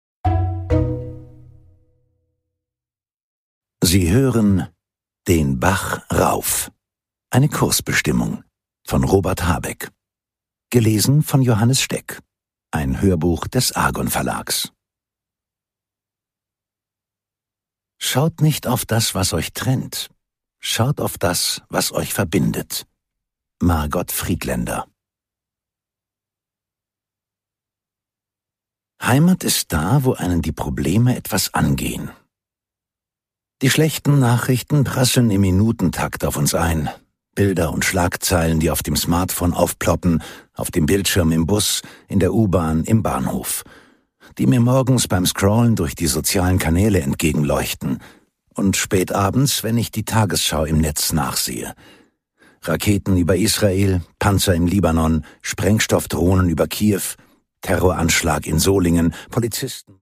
Robert Habeck: Den Bach rauf - Eine Kursbestimmung (Ungekürzte Lesung)
Produkttyp: Hörbuch-Download